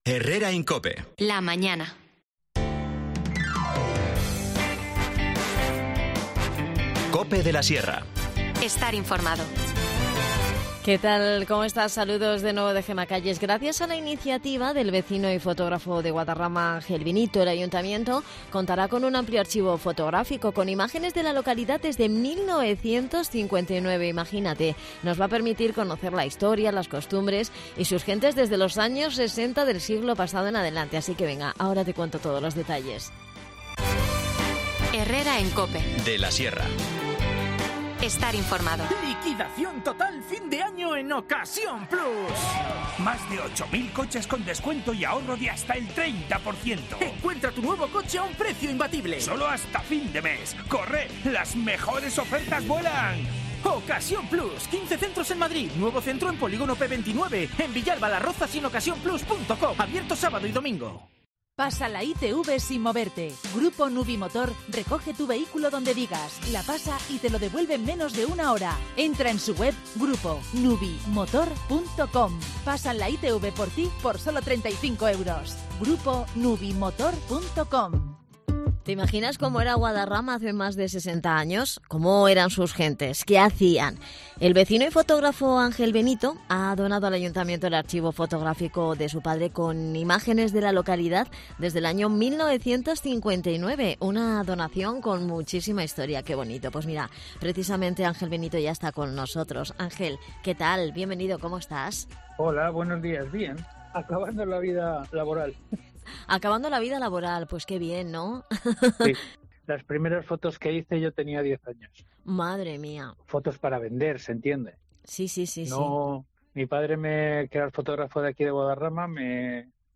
INFORMACIÓN LOCAL
Las desconexiones locales son espacios de 10 minutos de duración que se emiten en COPE, de lunes a viernes.